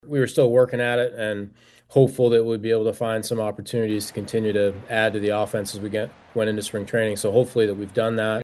Kelly and Cherington spoke on a Grapefruit League conference call and both addressed the Andrew McCutchen situation.